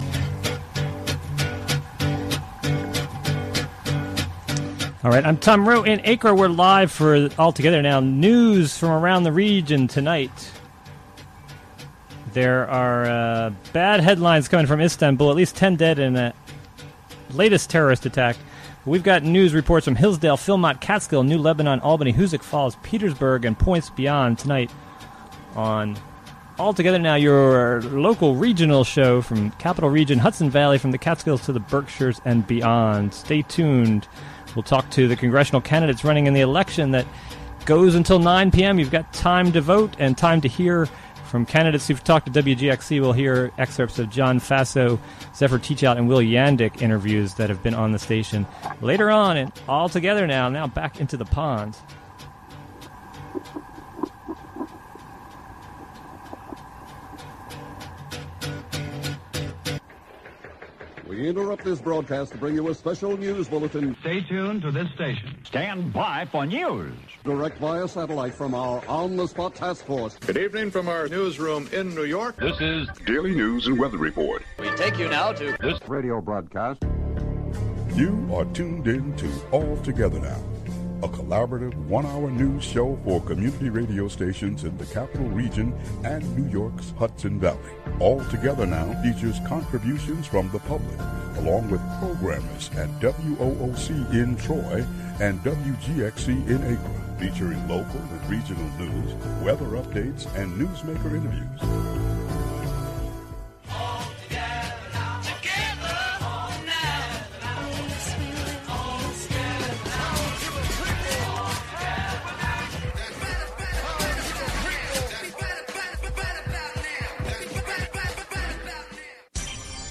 Daily evening news show for Hudson Valley region to Capitol District, from Catskills to the Berkshires.